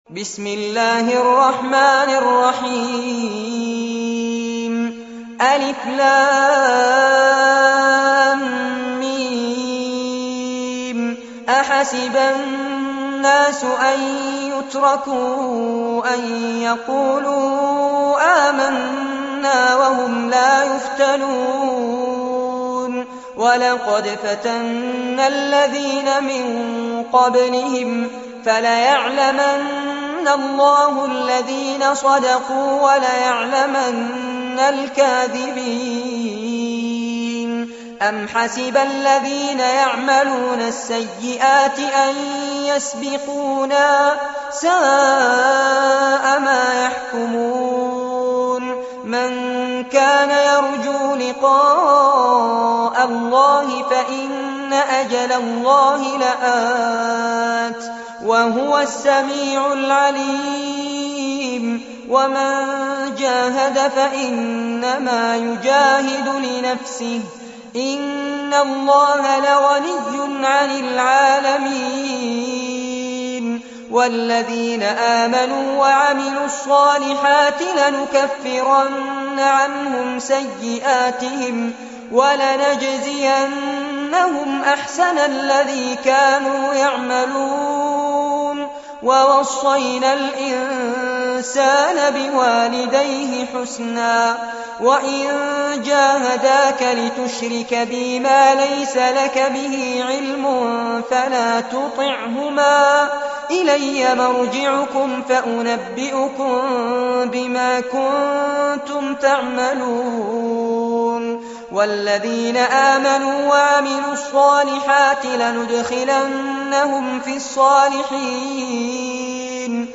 سورة العنكبوت- المصحف المرتل كاملاً لفضيلة الشيخ فارس عباد جودة عالية - قسم أغســـــل قلــــبك 2